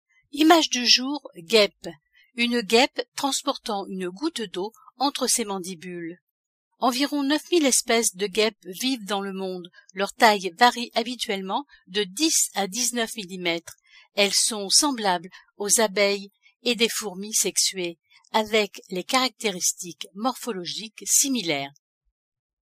Guêpe maçonne
guepe.mp3 (176.74 Ko) Environ 9000 espèces de guêpes vivent dans le monde.